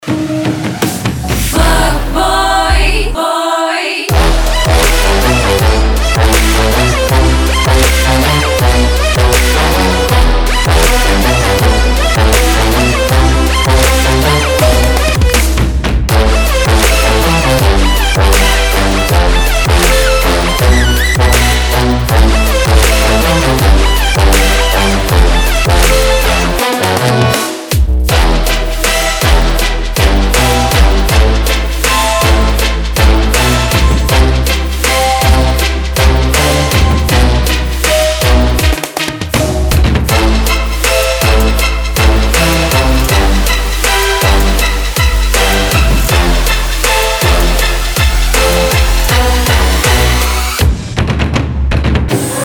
• Качество: 320, Stereo
громкие
мощные
Electronic
EDM
Trap
Стиль: trap